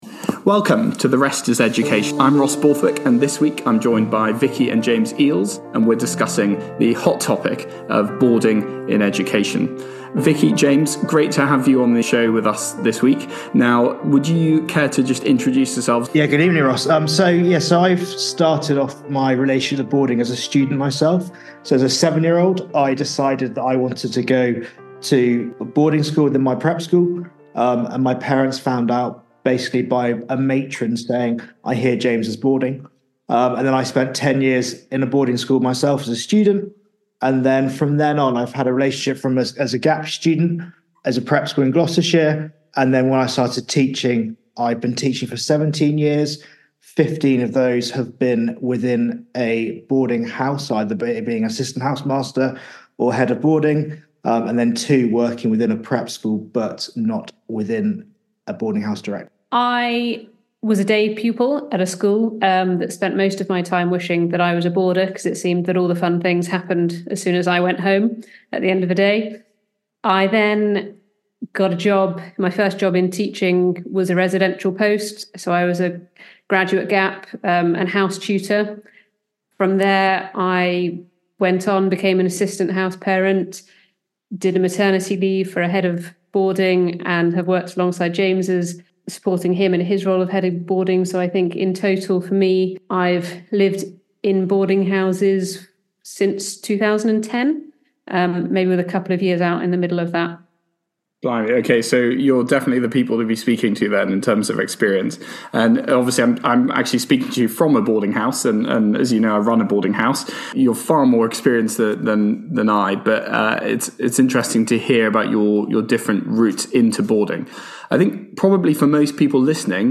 The trio discuss the reasons why, despite the stereotypes, boarding is on the rise*.